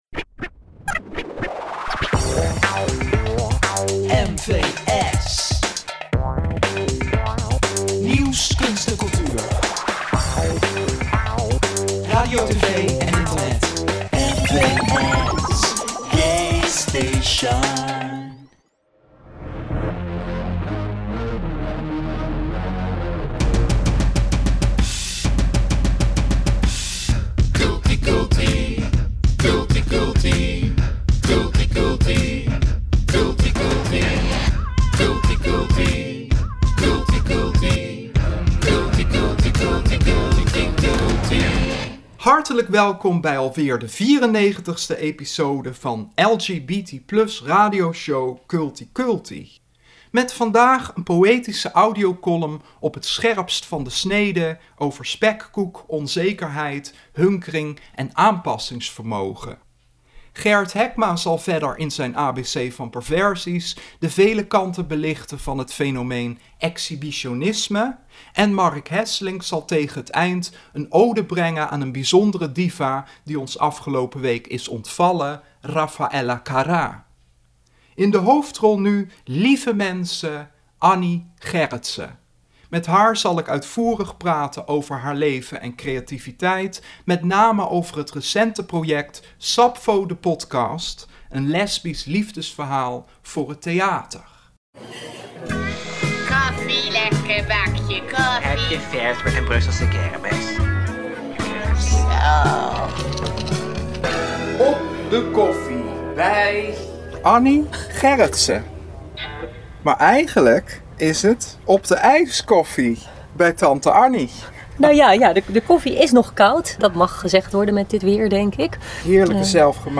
Audiocolumn
Kultipedia ter ere van Raffaella Carrà Interview